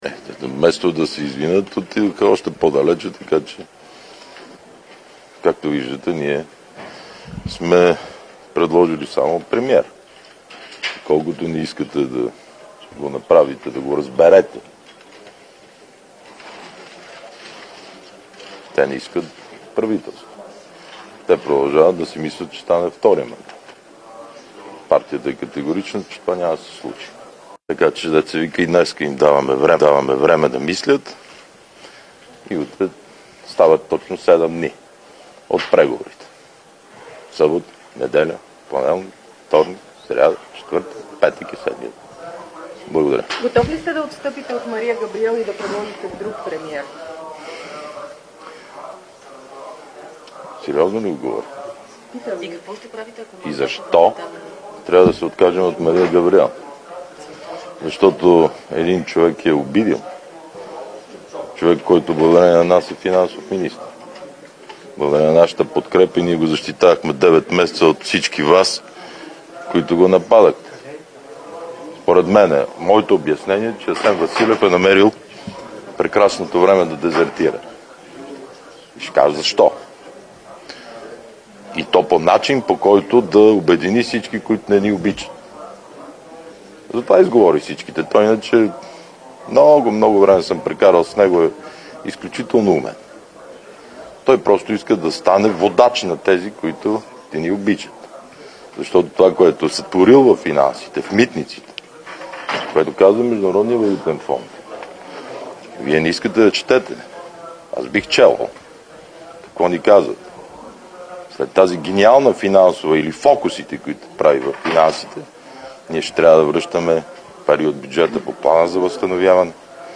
9.05 - Заседание на Народното събрание.
Директно от мястото на събитието